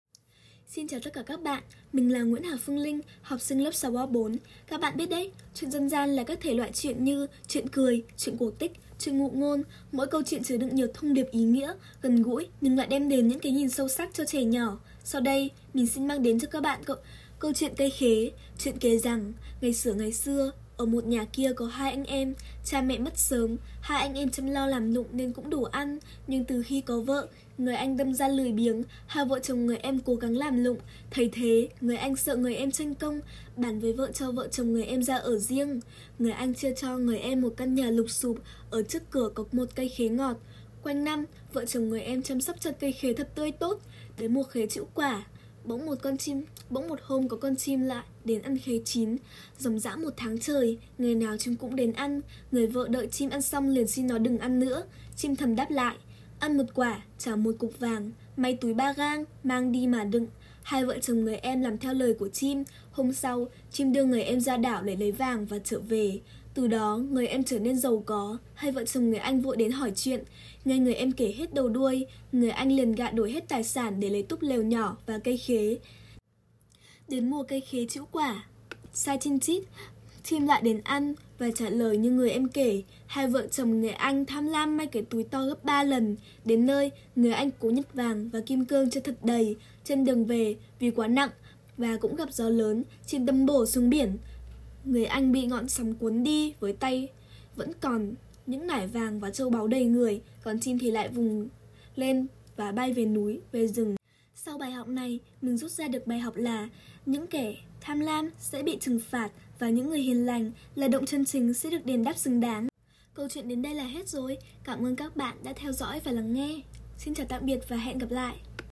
Sách nói | Truyện "Cây khế"